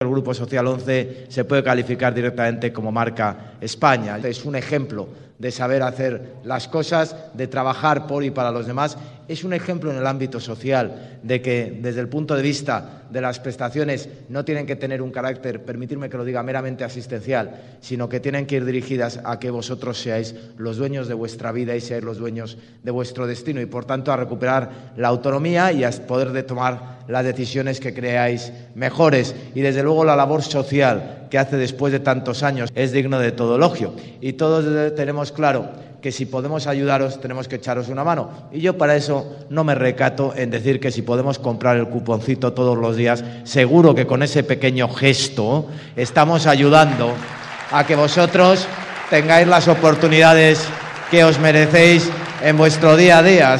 Hoy “es un día de descanso, de desconexión de la vida diaria, de tantas preocupaciones y, por tanto, es un día de disfrute”, resumía el alcalde de Madrid en la apertura de sus palabras de bienvenida a esas más de 1.200 personas reunidas dentro y fuera del auditorio.